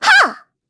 Xerah-Vox_Attack1_kr_Madness.wav